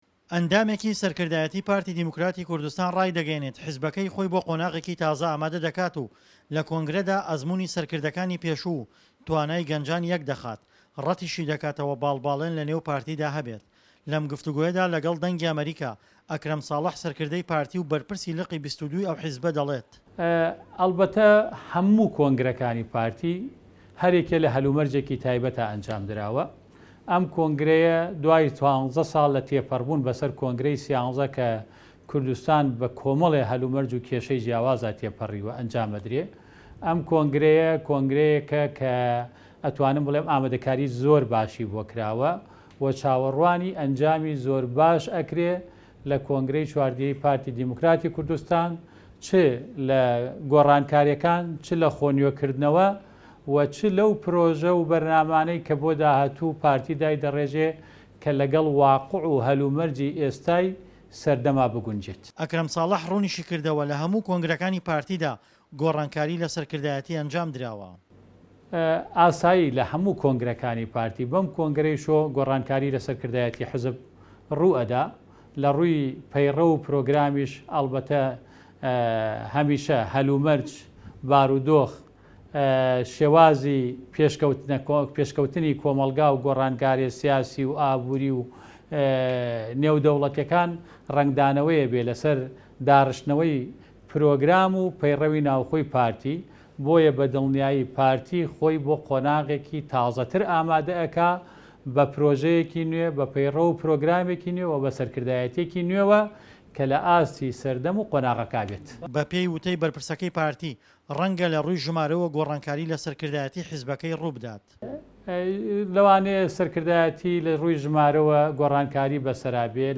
لەم گفتووگۆیەدا لەگەڵ دەنگی ئەمەریکا